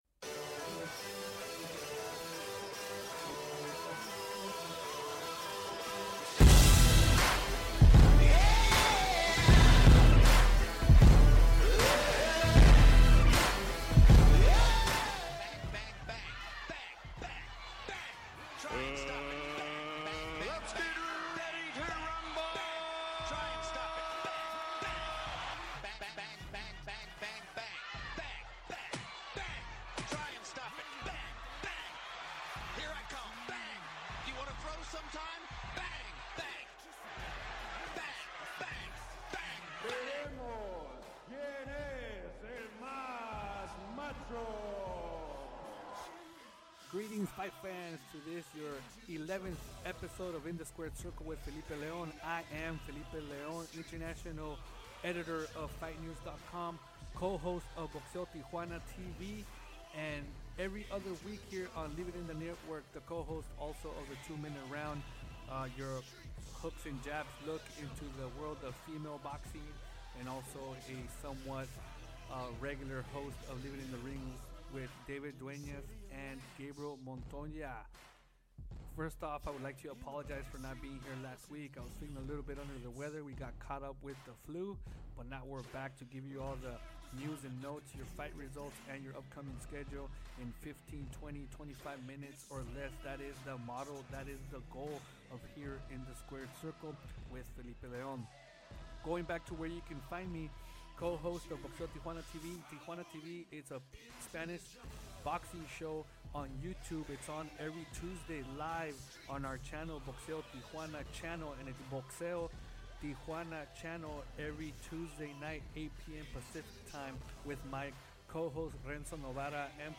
passes the latest news in the sport with a fast pace style of 30 minutes or less.